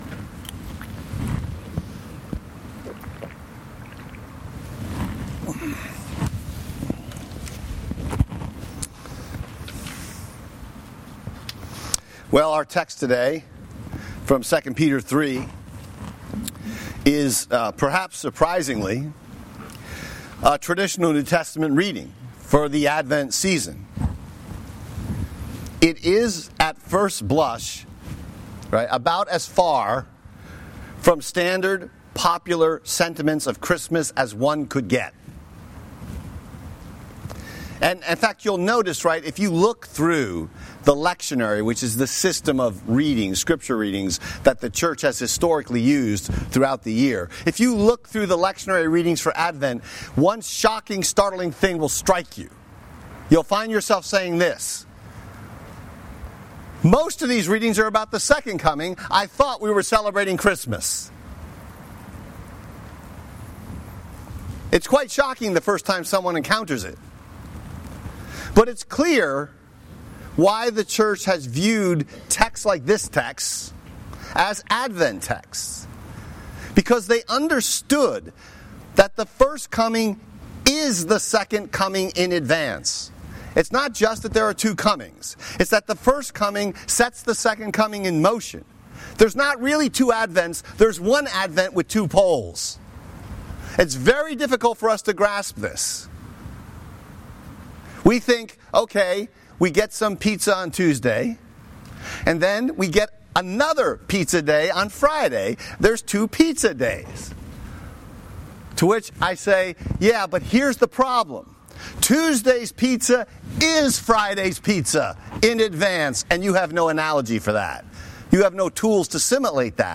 Sermon text: 2 Peter 3:8-14